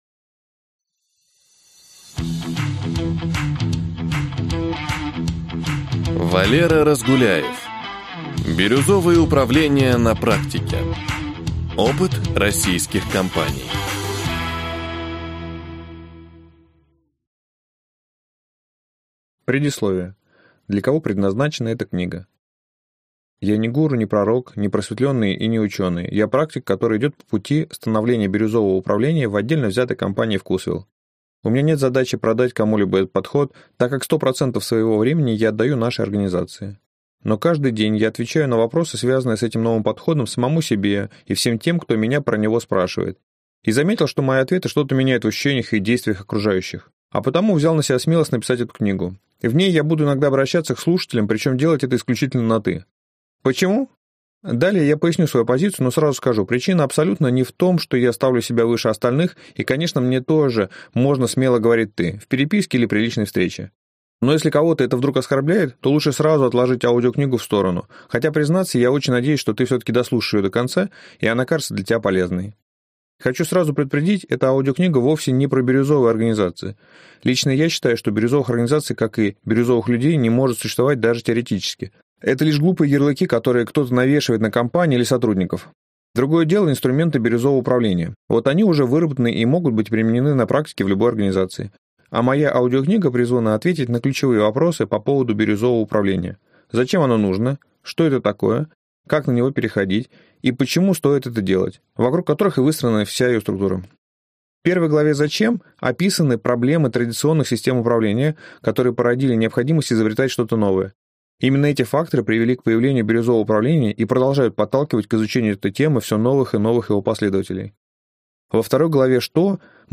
Аудиокнига Бирюзовое управление на практике | Библиотека аудиокниг